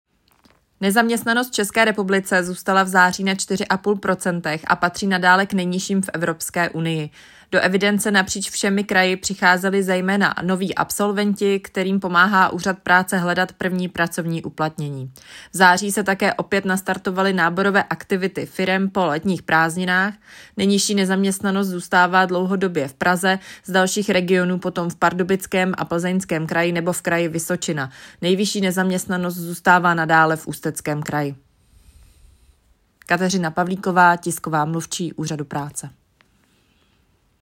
Komentář